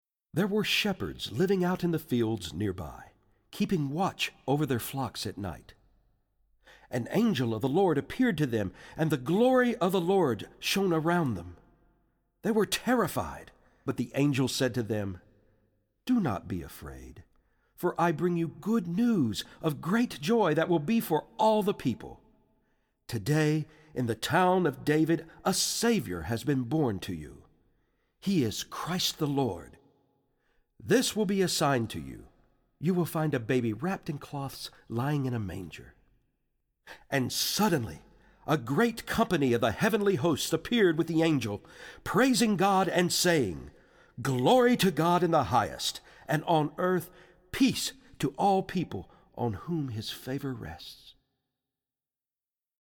Voicing: Full Orch - CD-ROM